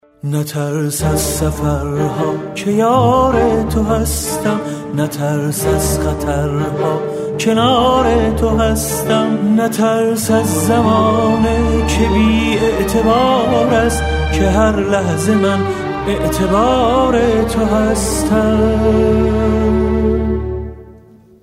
زنگ موبایل با کلام با ملودی احساسی